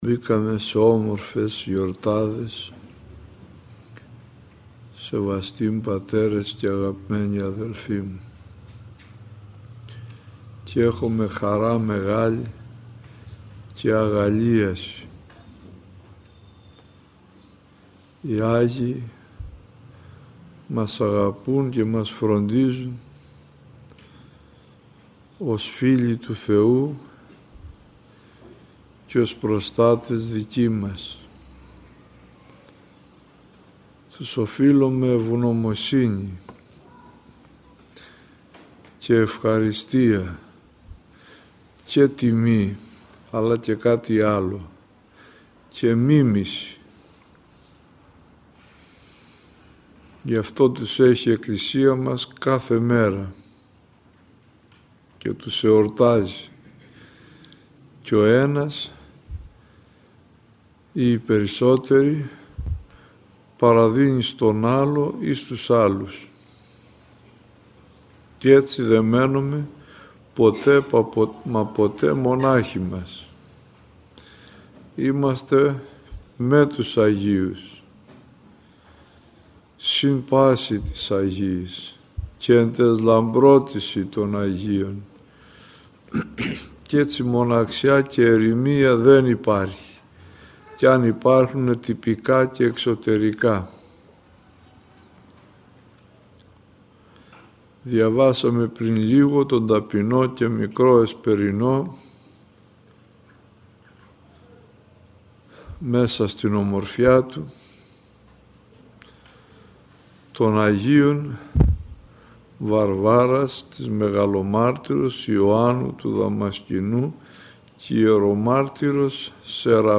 Συναξάριον, Ακολουθία, Παρακλ. Κανών, ομιλία